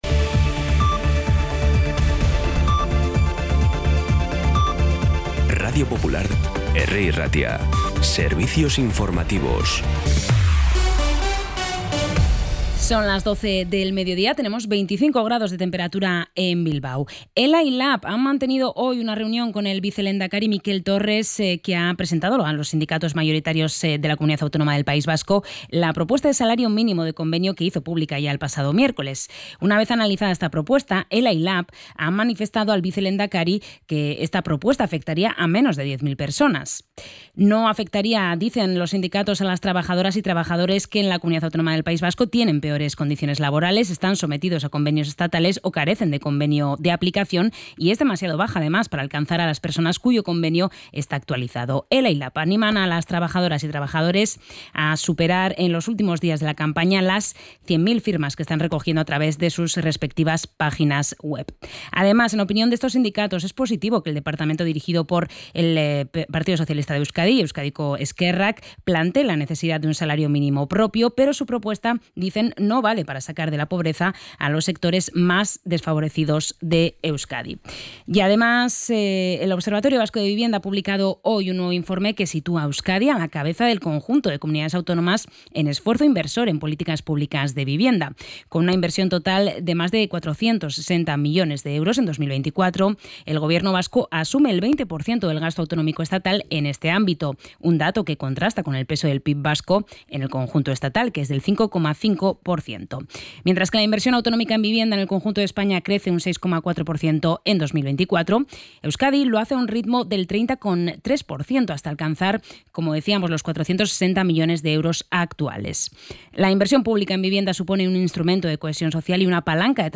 Información y actualidad desde las 12 h de la mañana